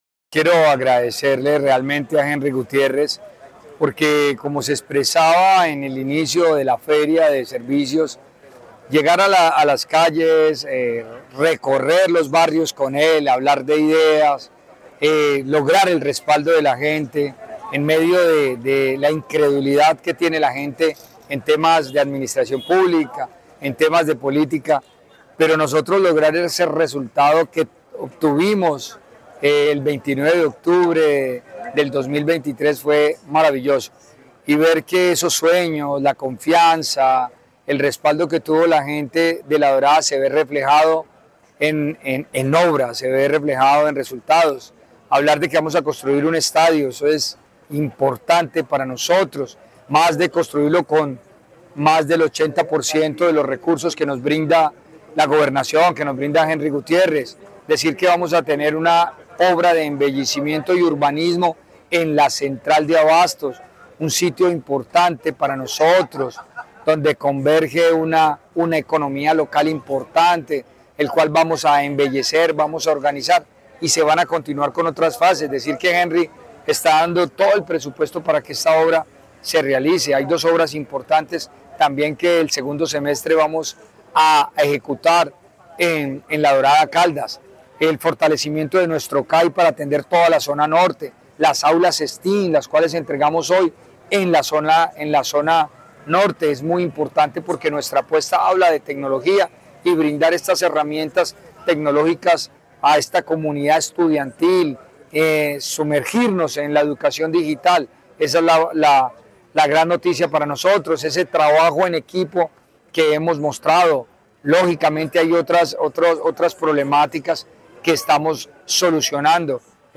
Alcalde del municipio de La Dorada, Fredy Saldaña.